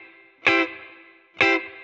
DD_TeleChop_130-Cmaj.wav